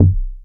GIGA C3.wav